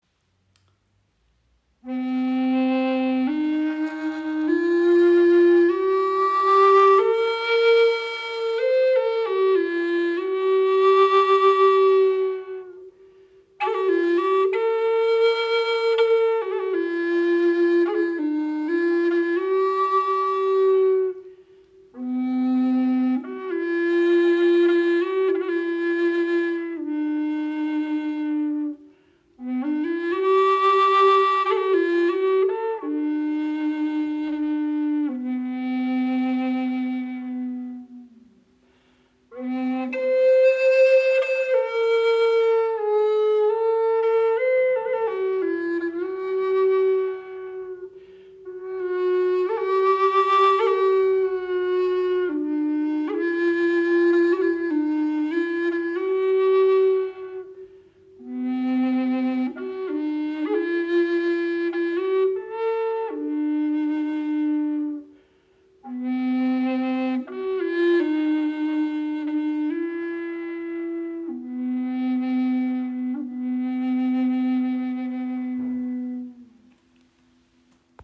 Gebetsflöte in tiefem C - 432 Hz
• Icon 68 cm lang, 6 Grifflöcher